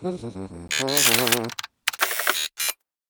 droneReloading1.wav